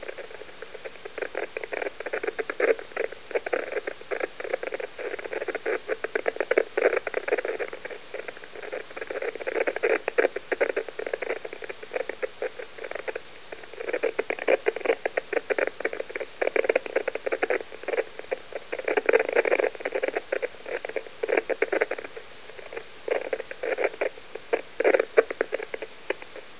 Daha sonra tekrar dinlemek veya üzerinde çalışmak için Jüpiter’in seslerini kaydetmek isteyebilirsiniz.
Kayıt-2 (S-Bursts)